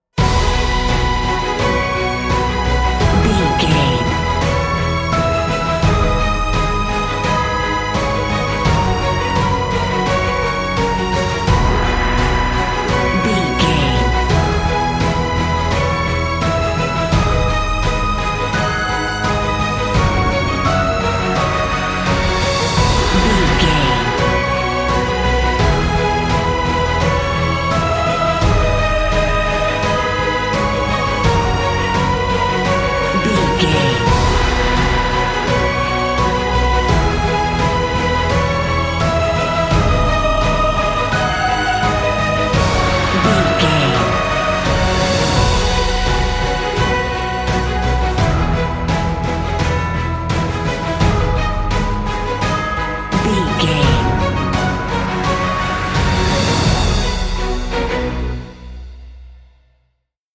Uplifting
Ionian/Major
orchestral
brass
cello
percussion
strings
violin